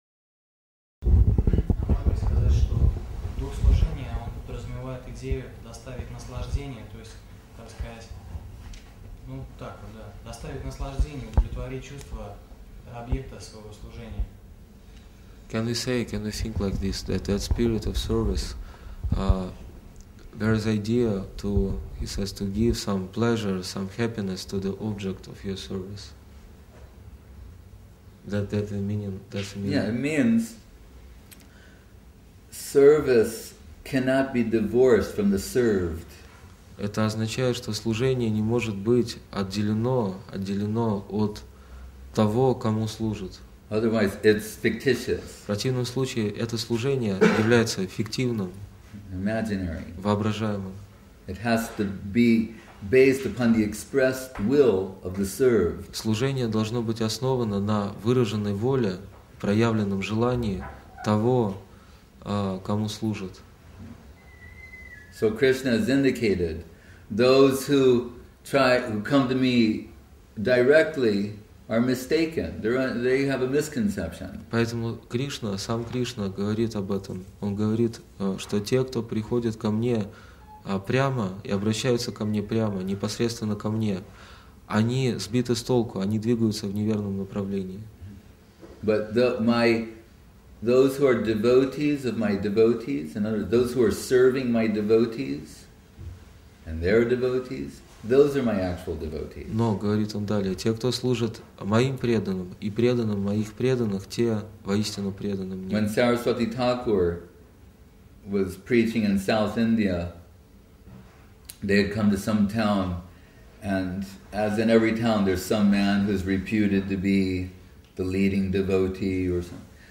Место: Культурный центр «Шри Чайтанья Сарасвати» Москва